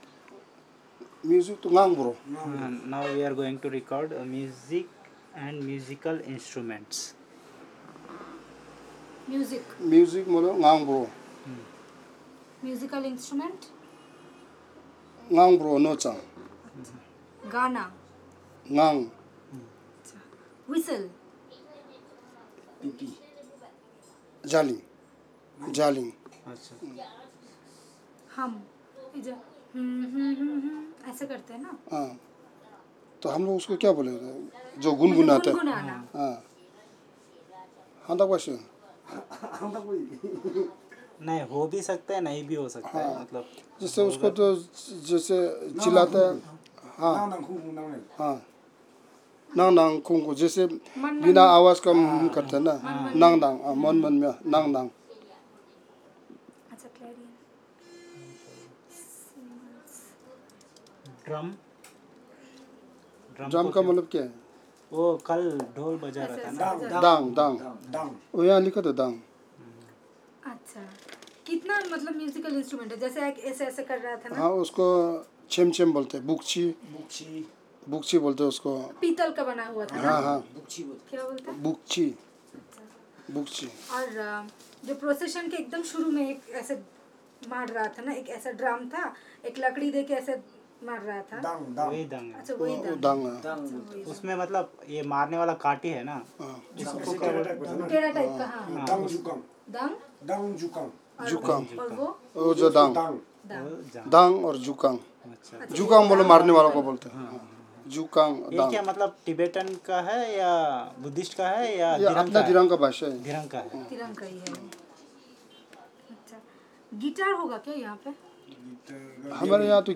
Elicitation of words about music and musical instruments